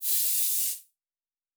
pgs/Assets/Audio/Sci-Fi Sounds/MISC/Air Hiss 2_05.wav at master
Air Hiss 2_05.wav